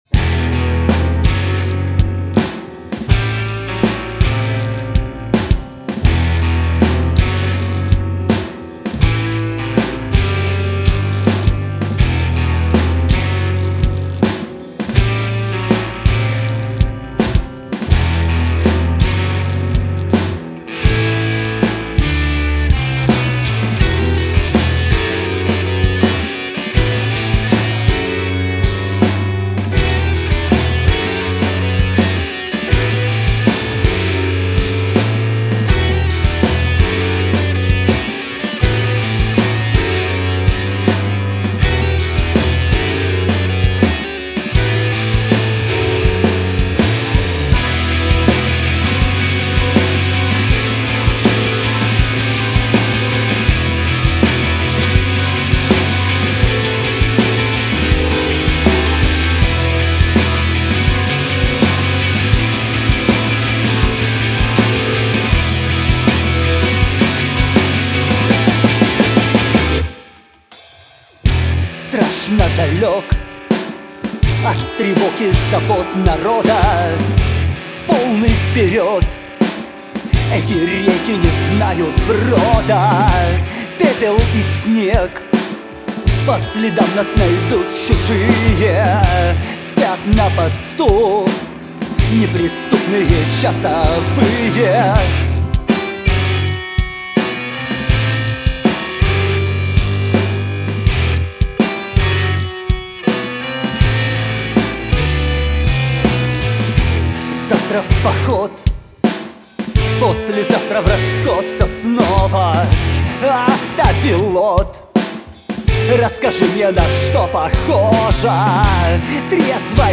ударные
саксофон